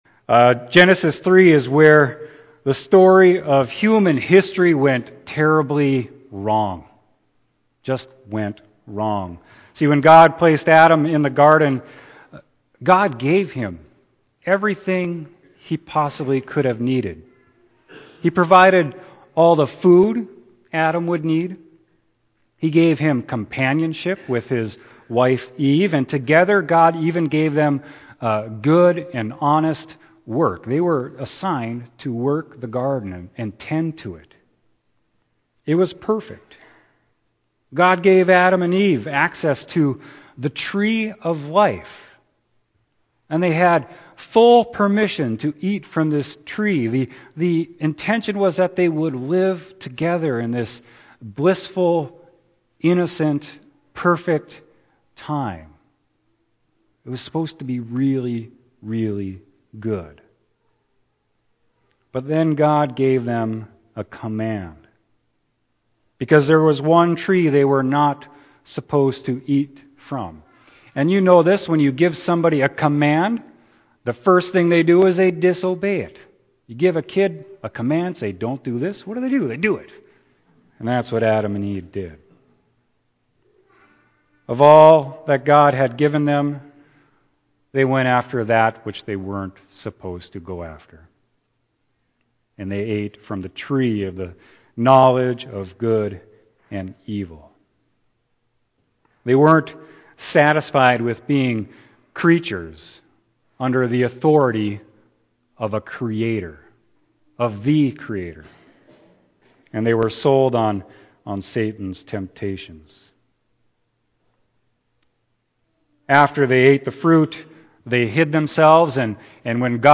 Amen Sermon: John 14.1-7